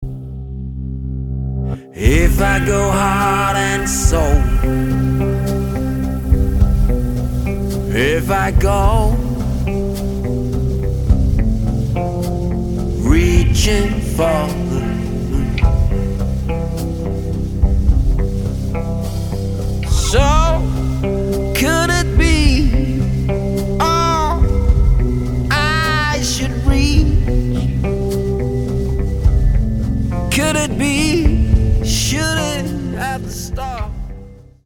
• Americana
• Blues
• Indie
• Rock
Vokal
Trommer